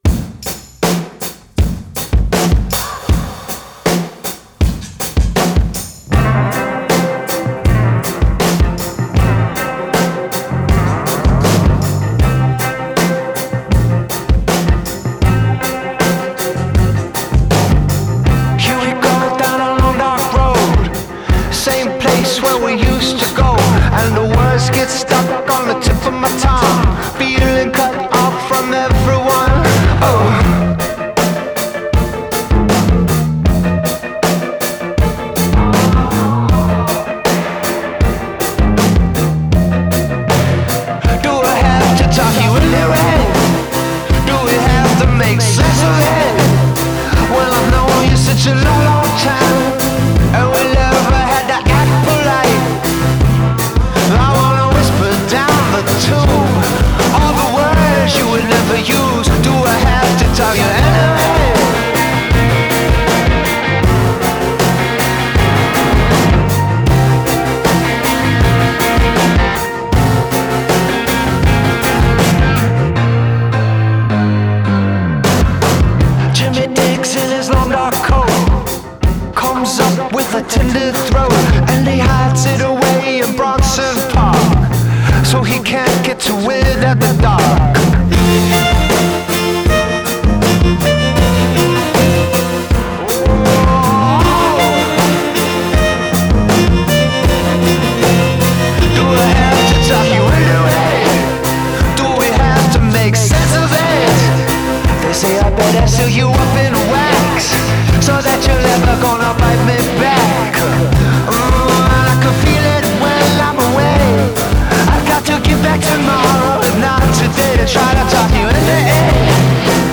held together by a funky vamp
trademark snarl